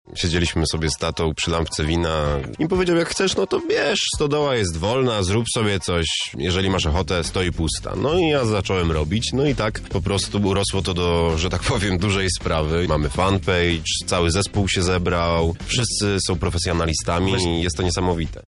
O nietypowych początkach mówi pomysłodawca